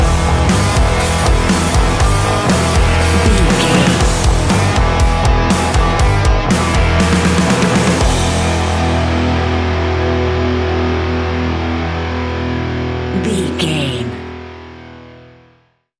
Ionian/Major
E♭
heavy metal